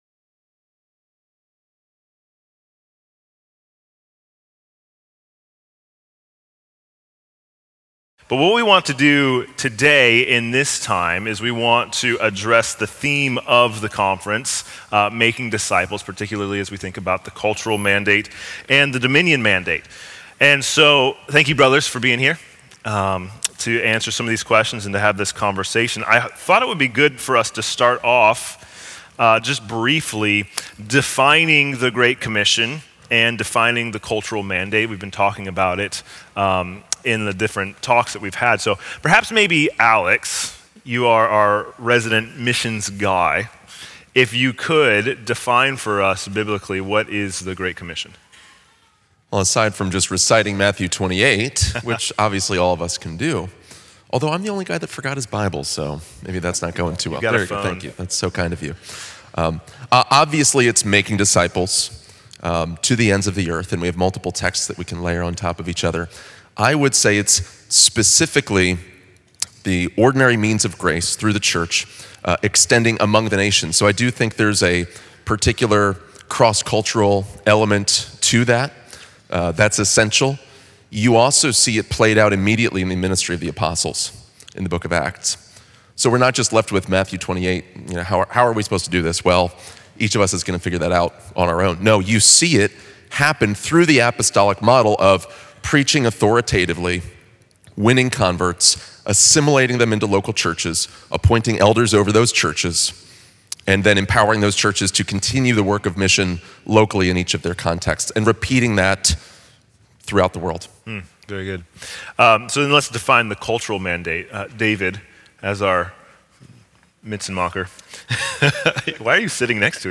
Make Disciples Panel Discussion